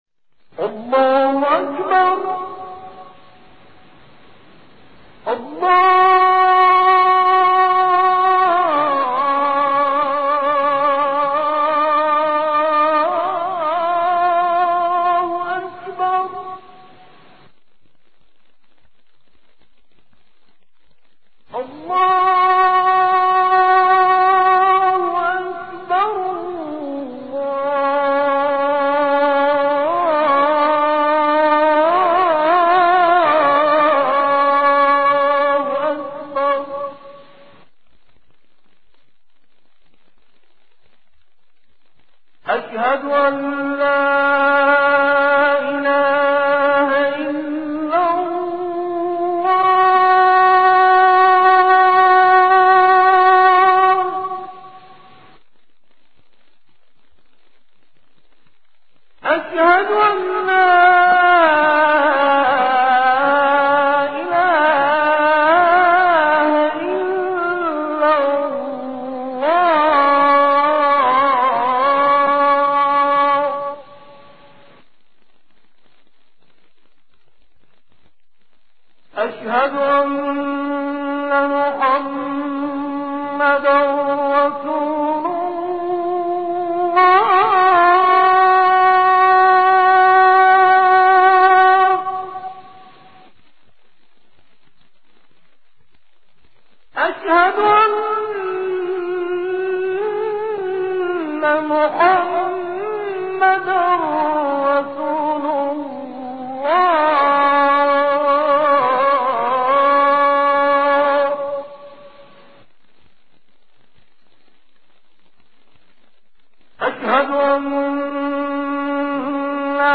Azan_Haram_Emam_Reza.mp3
Azan-Haram-Emam-Reza.mp3